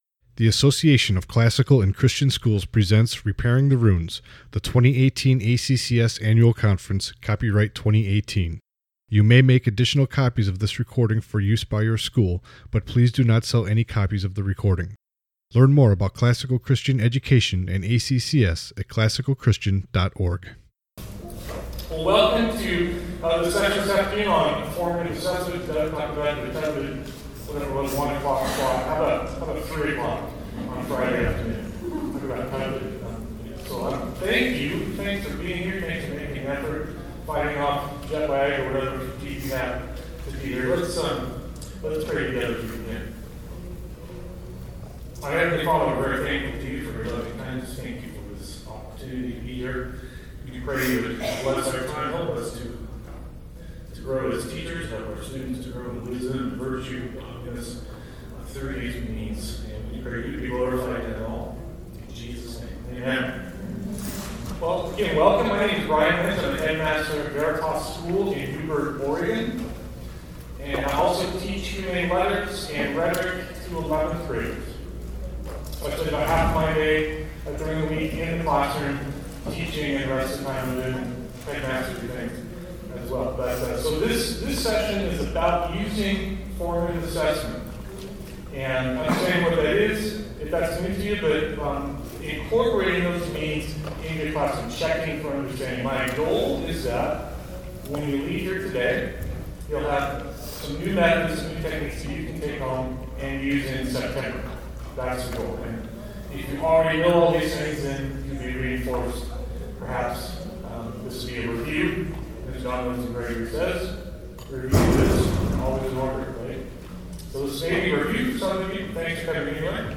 Jan 15, 2019 | All Grade Levels, Conference Talks, Foundations Talk, General Classroom, Library, Media_Audio, Virtue, Character, Discipline | 0 comments
The Association of Classical & Christian Schools presents Repairing the Ruins, the ACCS annual conference, copyright ACCS.